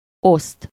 Ääntäminen
US GenAm: IPA : /ʃɛɚ/ RP : IPA : /ˈʃɛə/